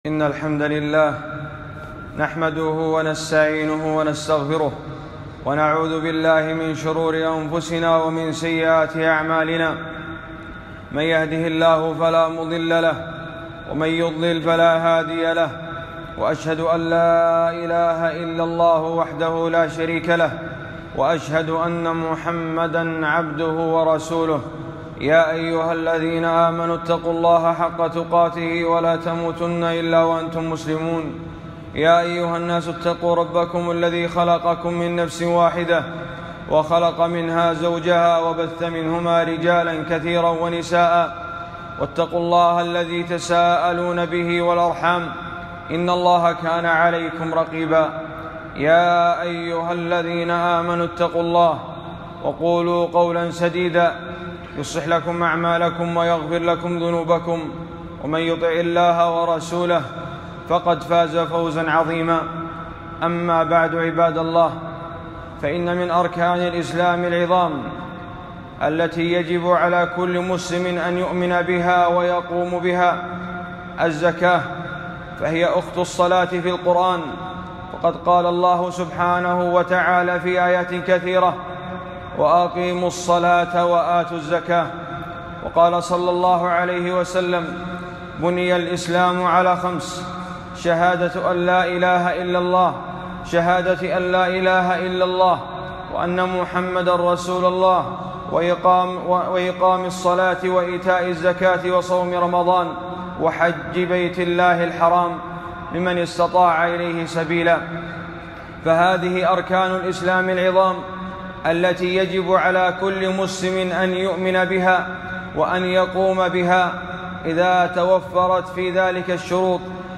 خطبة - مسائل مهمات في الزكاة